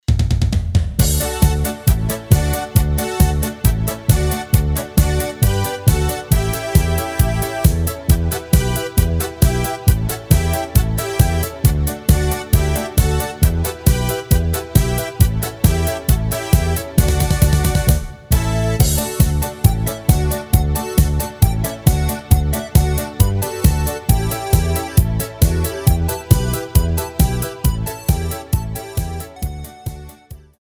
Rubrika: Národní, lidové, dechovka
Karaoke